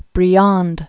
(brē-änd, -äɴ), Aristide 1862-1932.